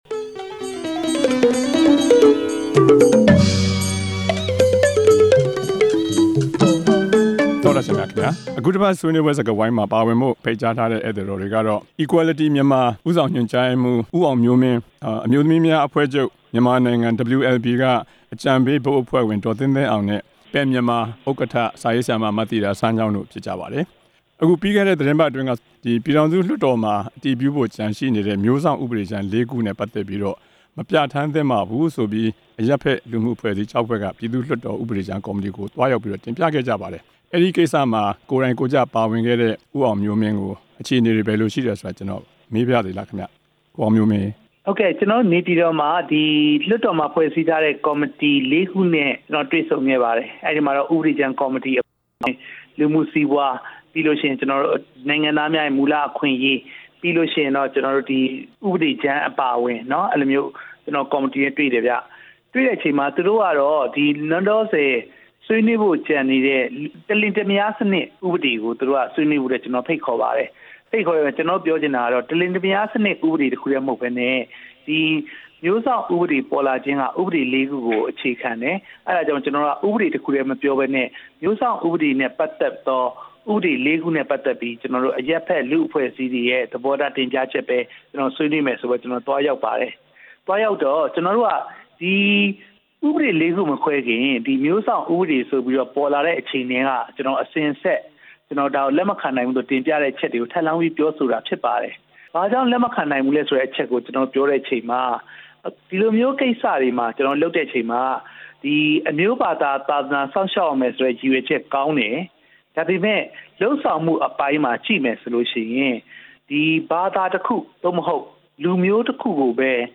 အဖွဲ့အစည်း ၆ ဖွဲ့က မျိုးစောင့် ဥပဒေကြမ်း လေးခုကို သဘော မတူတဲ့အကြောင်း ဆွေးနွေးချက်